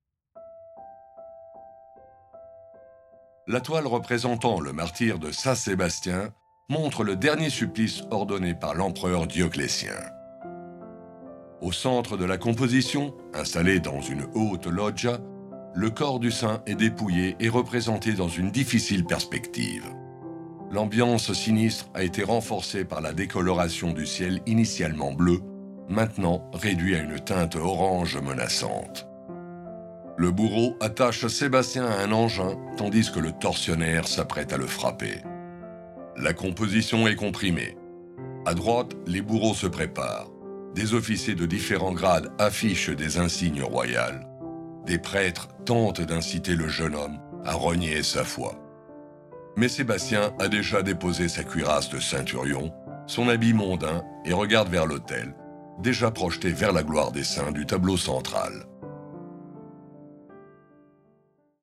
Le nostre tracce audio sono prodotte da professionisti del settore cinematografico: attori madrelingua e compositori professionisti danno vita a una colonna sonora originale per raccontare il tuo museo.